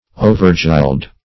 Overgild \O`ver*gild"\